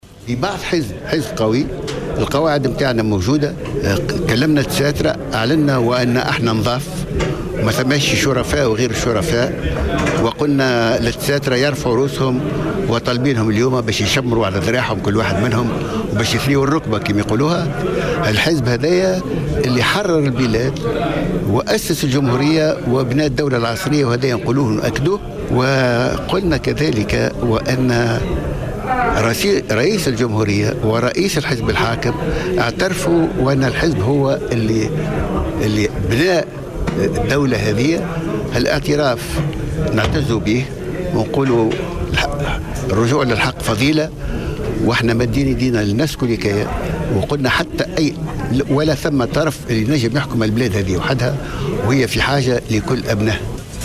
دعا مؤسس الحركة الدستورية حامد القروي على هامش ندوة صحفية نظمتها الهئية السياسية للحركــة اليوم السبت 9 نوفمبر 2013 الى المصالحة مع باقي الاطراف السياسية الأخرى من أجل انقاذ البلاد من الأزمة الحالية التي تمر بها البلاد مضيفا في السياق ذاته أنه ليس هناك اي طرف قادر على أن يحكم تونس بمفرده وأن تونس تحتاج إلى جميع أبنائها .من جهة اخرى أشار القروي إلى أن الدستوريين قدموا الكثير لتونس وحرروا البلاد وقاموا ببناء الدولة العصرية وتأسيس الجمهورية على حد قوله